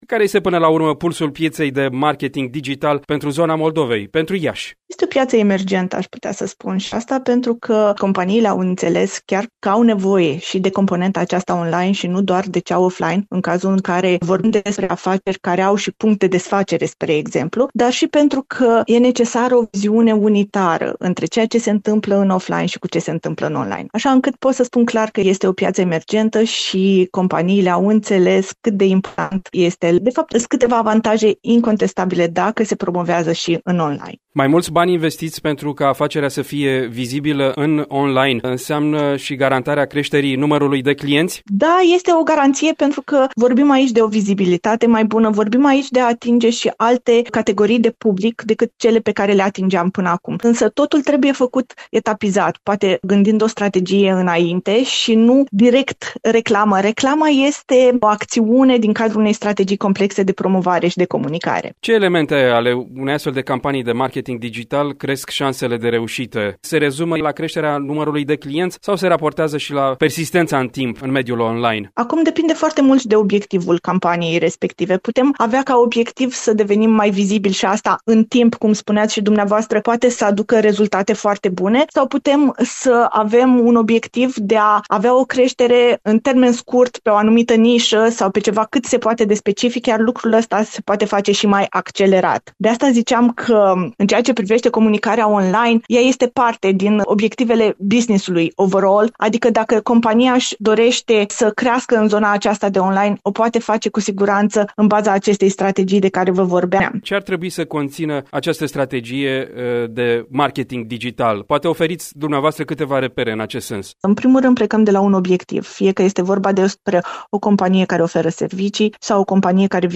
Varianta audio a dialogului: